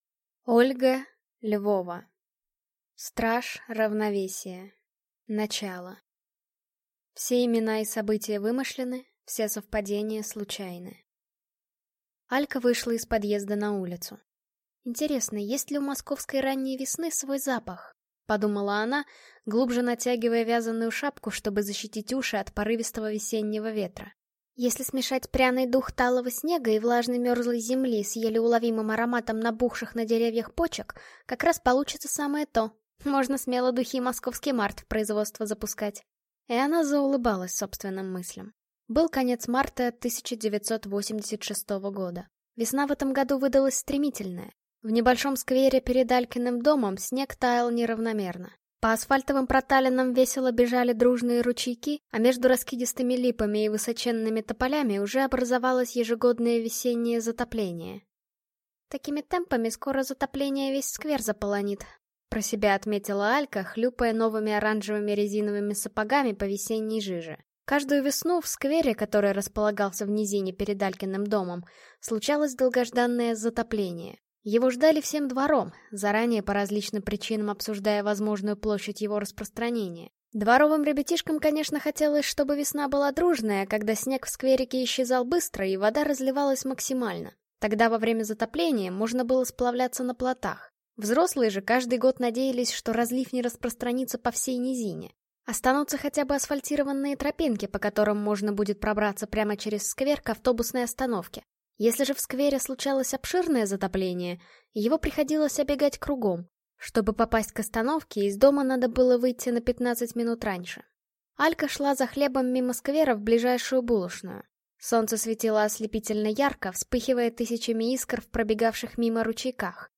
Аудиокнига Страж Равновесия. Начало | Библиотека аудиокниг